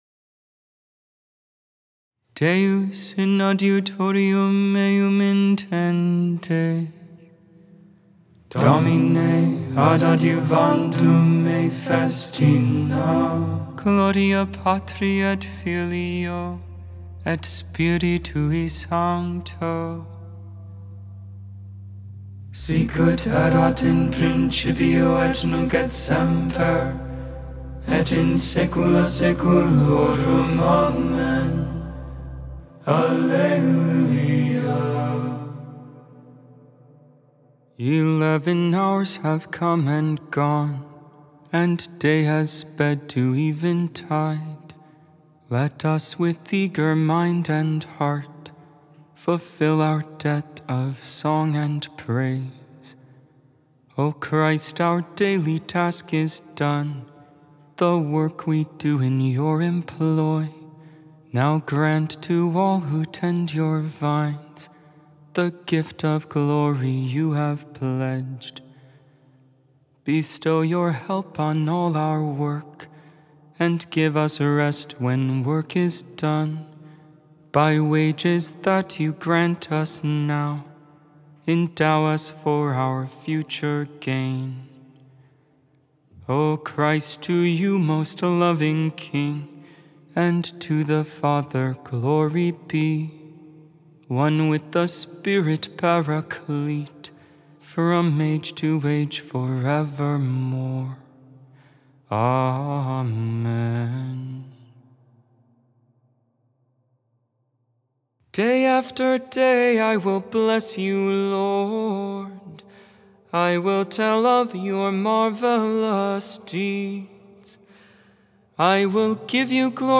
2.7.25 Vespers, Friday Evening Prayer of the Liturgy of the Hours
Mozarabic Hymn